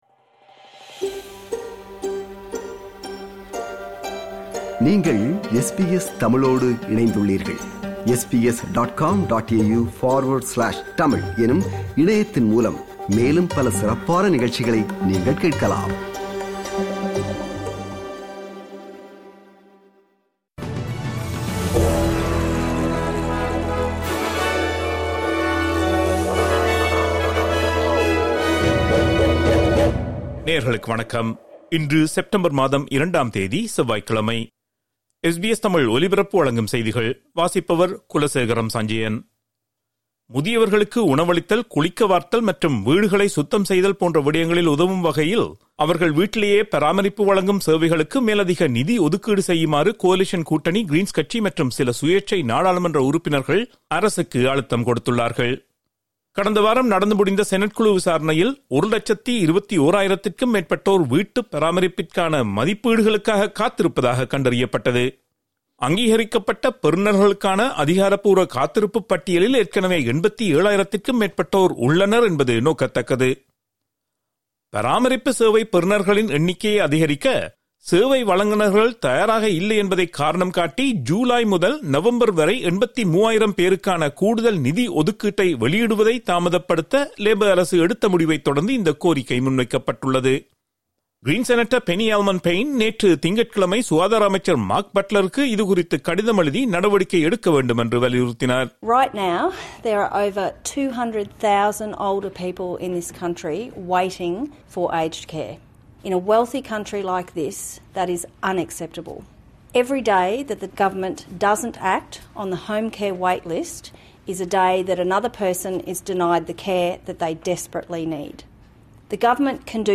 SBS தமிழ் ஒலிபரப்பின் இன்றைய (செவ்வாய்க்கிழமை 02/09/2025) செய்திகள்.